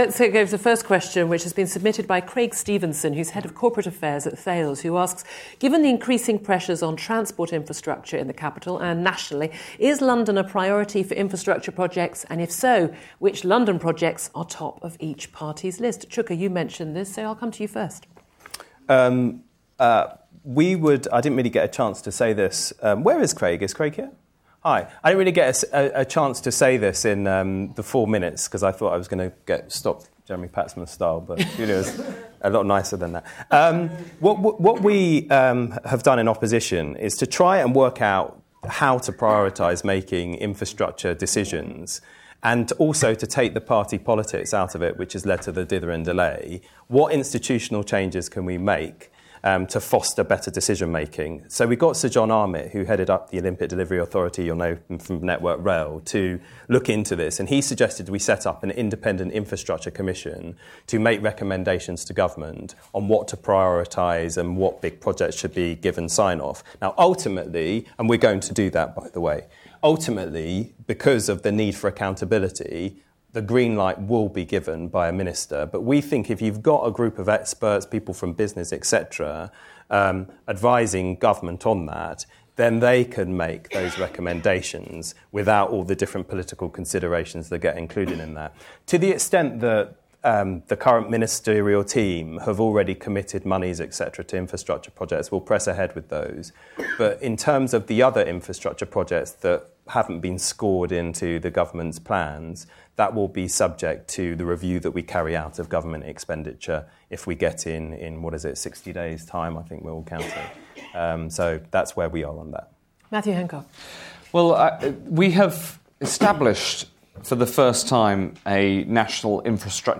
Listen to Matt Hancock MP, Chuka Umunna MP and Vince Cable MP talk about transport investment in London.